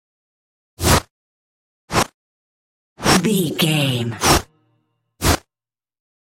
Passby fast whoosh
Sound Effects
Fast
bouncy
futuristic
intense
pass by
car